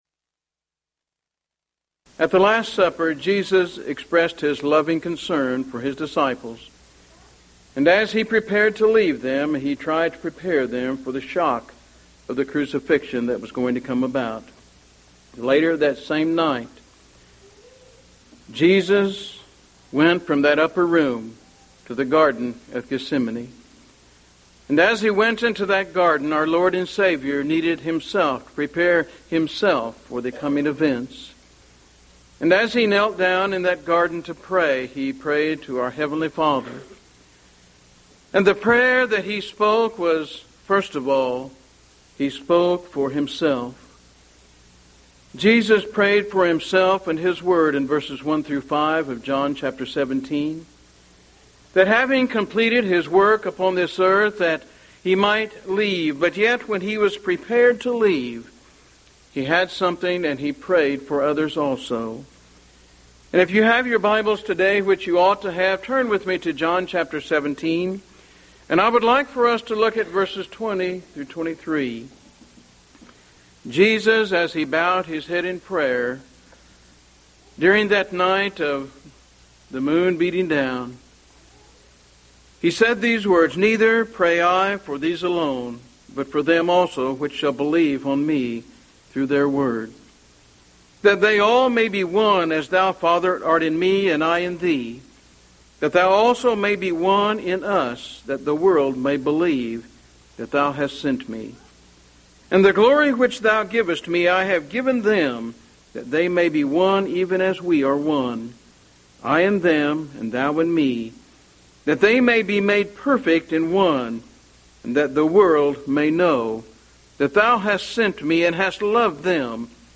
Mid-West Lectures